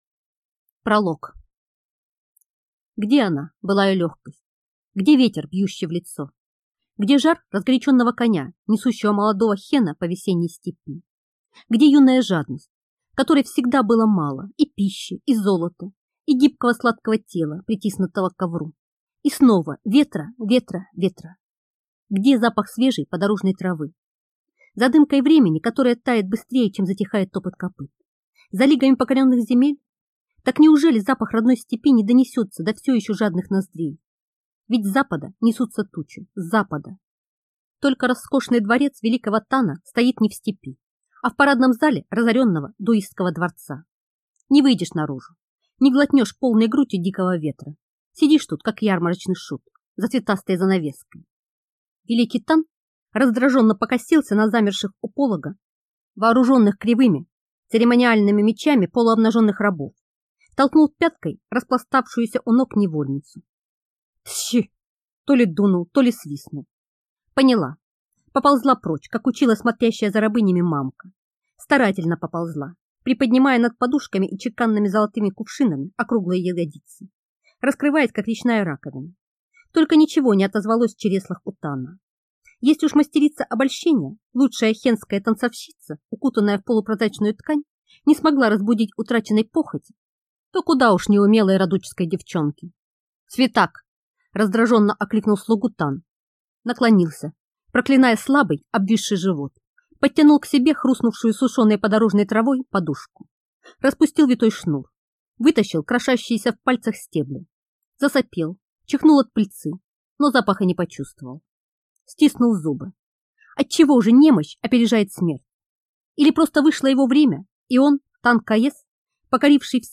Аудиокнига Оправа для бездны | Библиотека аудиокниг